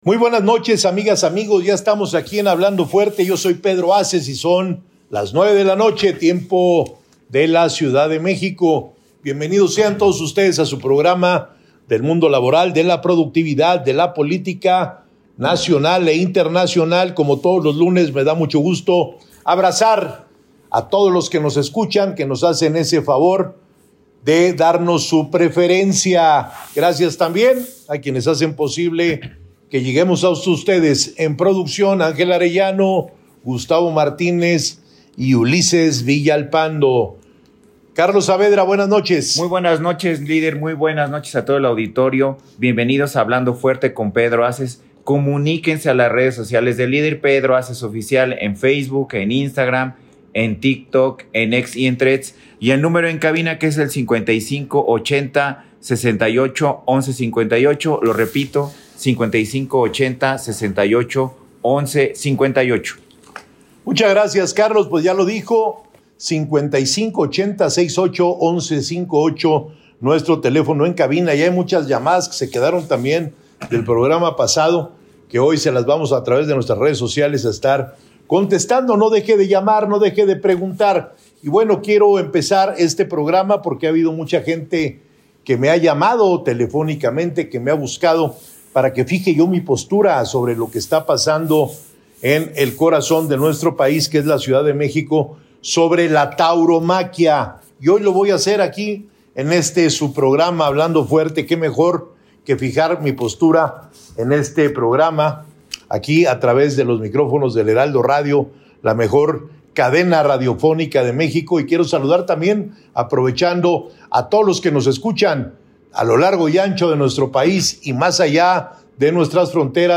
Durante su programa de radio “HablandoFuerte” el diputado federal Pedro Haces Barba manifestó su postura en torno ala iniciativa que en días pasados presentó la Jefa de Gobierno de la CDMX, ClaraBrugada, para modificar las corridas de toros.